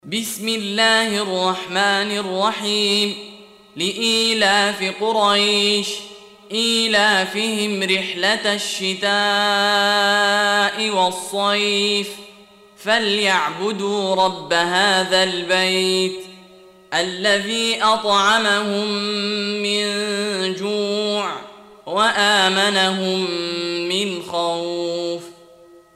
Surah Repeating تكرار السورة Download Surah حمّل السورة Reciting Murattalah Audio for 106. Surah Quraish سورة قريش N.B *Surah Includes Al-Basmalah Reciters Sequents تتابع التلاوات Reciters Repeats تكرار التلاوات